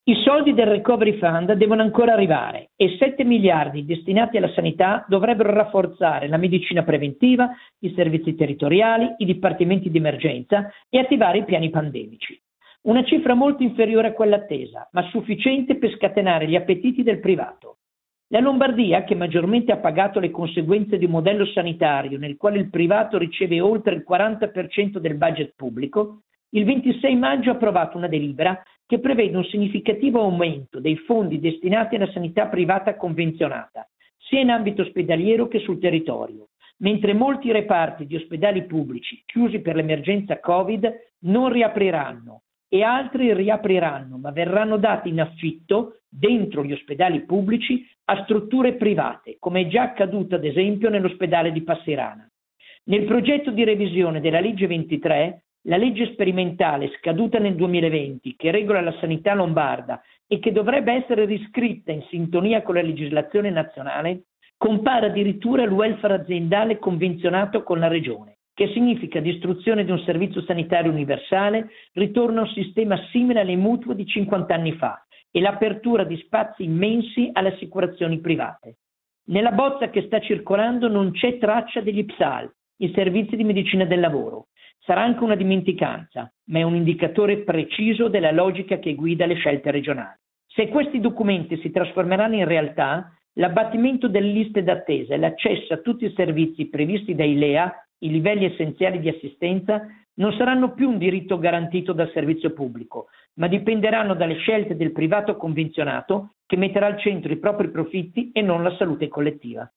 Il commento di Vittorio Agnoletto, medico e autore della trasmissione 37 e 2.